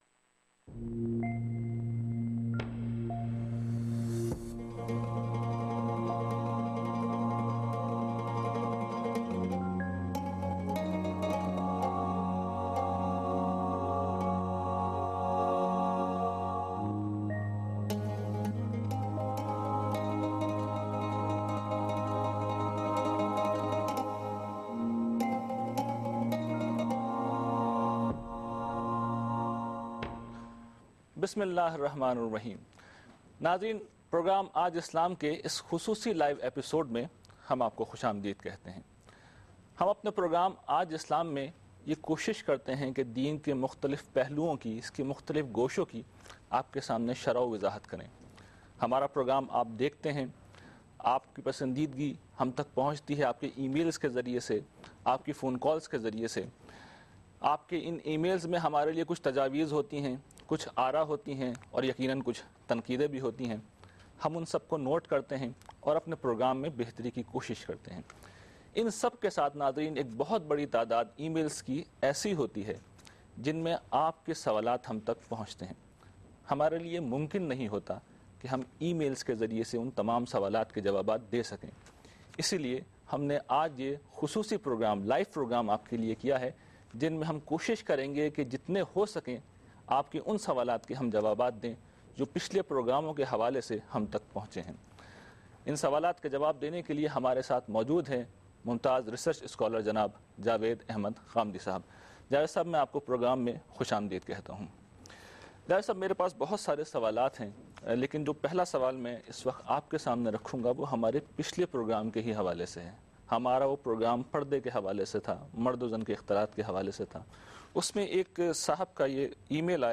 Live Q & As